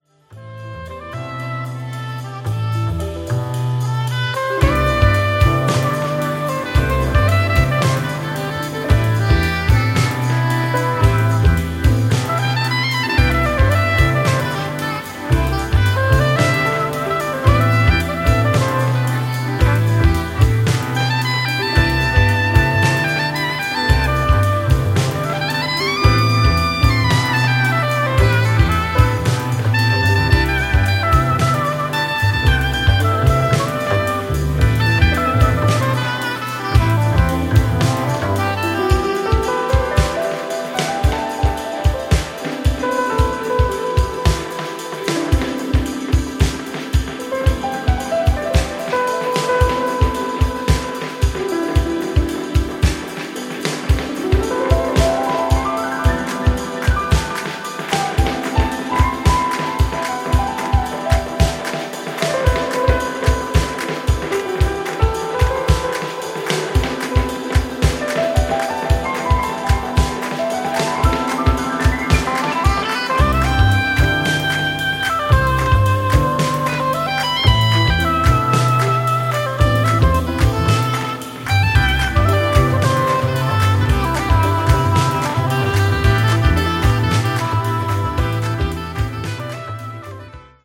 ジャンル(スタイル) ELECTRONICA / BALEARICA